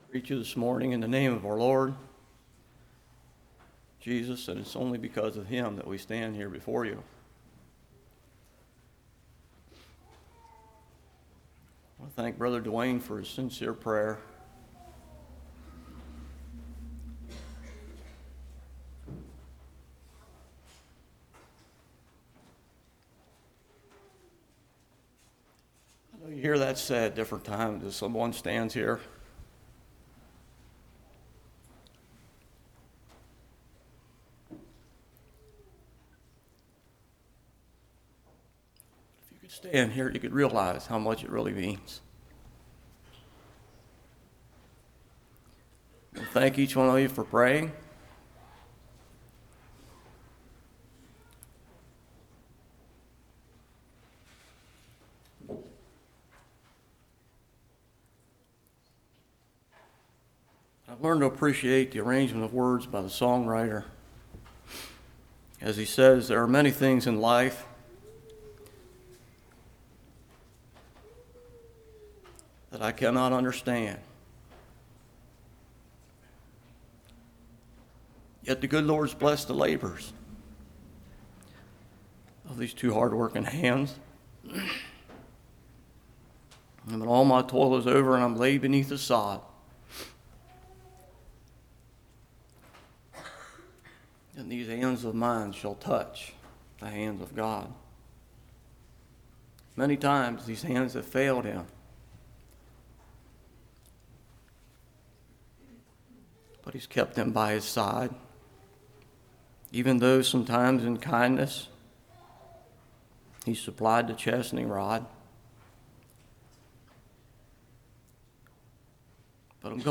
Passage: James 3:1-18 Service Type: Morning Power of Tongue Electronic speaking Scriptures on tongue « Word Pictures Joshua